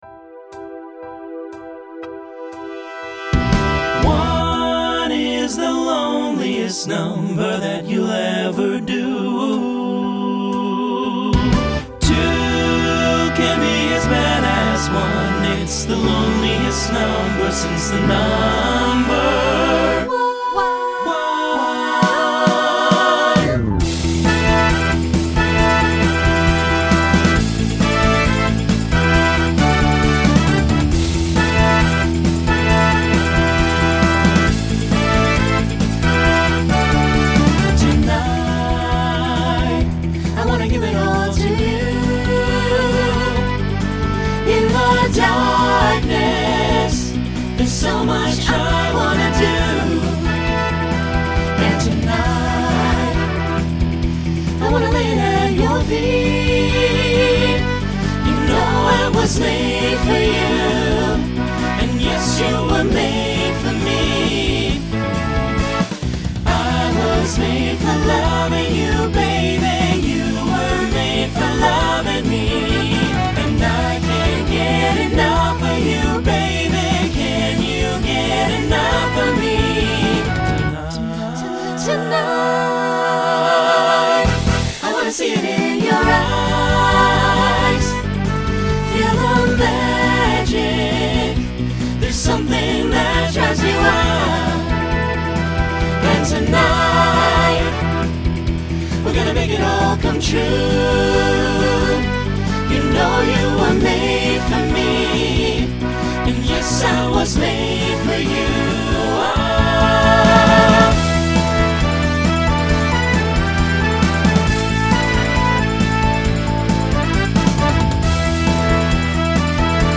Genre Rock Instrumental combo
Voicing SATB